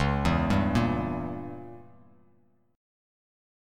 C#dim7 chord